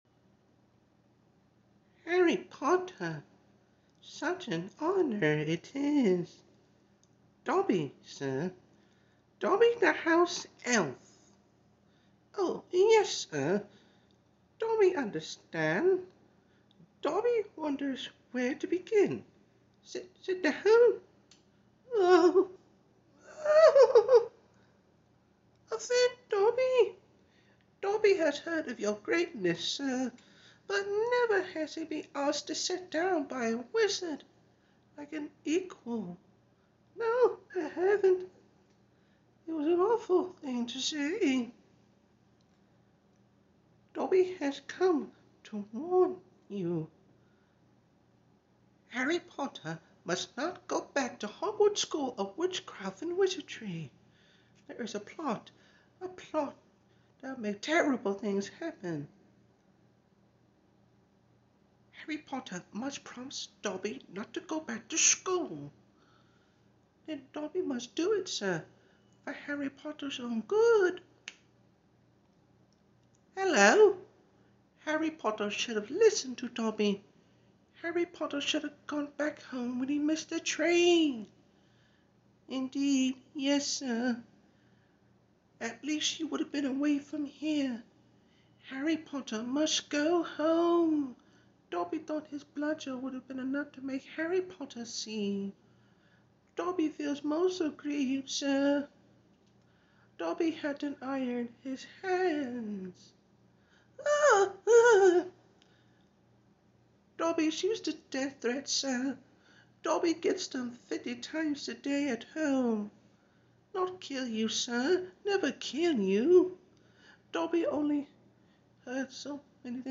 Voice impression of Dobby the sound effects free download